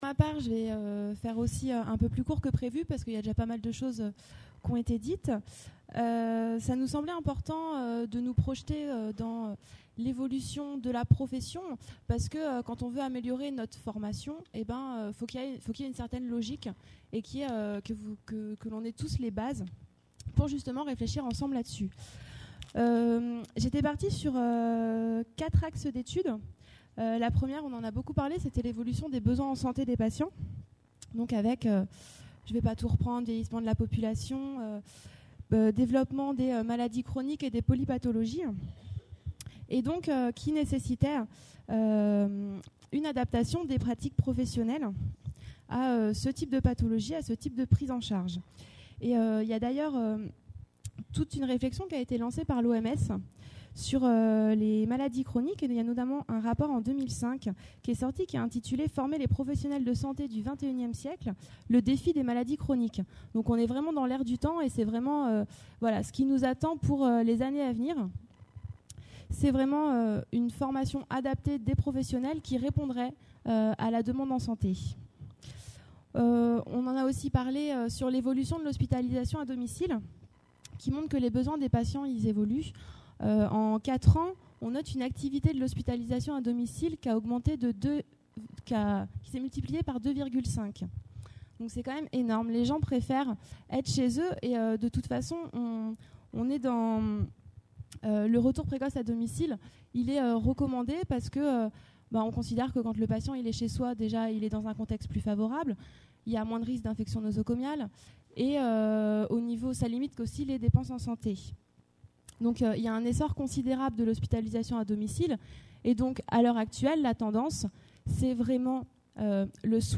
Conférence enregistrée lors 10ème Congrès National des Etudiants en Soins Infirmiers (FNESI) – Paris le 26 novembre 2010 - L’évolution générale de l’offre de